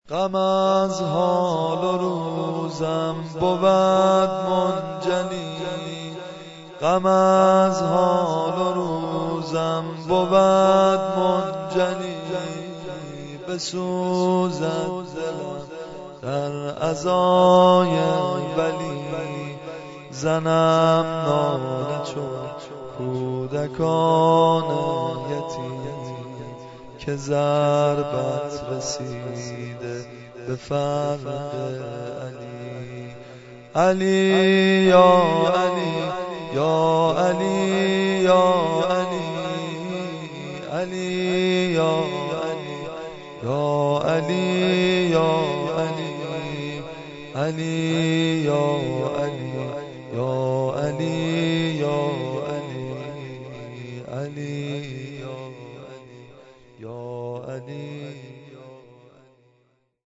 متن مداحی شهادت حضرت علی (ع) در شب نوزدهم رمضان با سبک واحد -( غم از حال و روزم بُود منجلی )